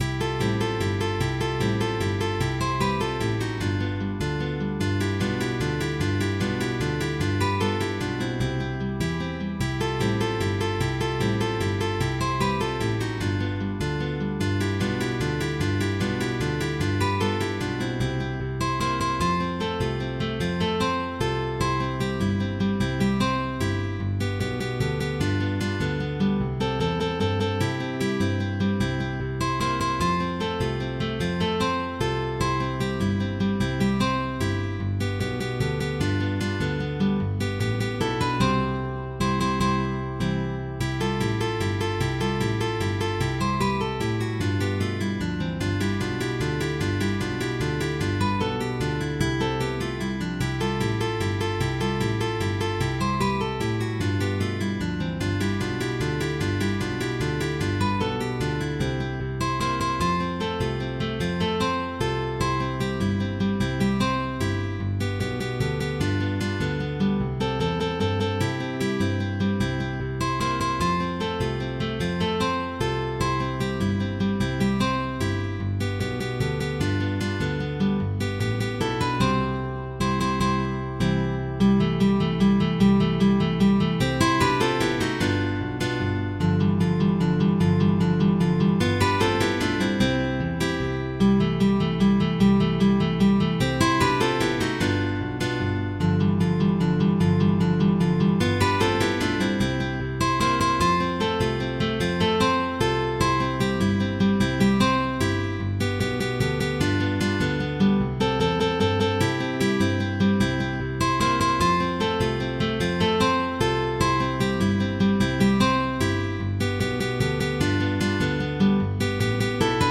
Guitar trio sheetmusic
GUITAR TRIO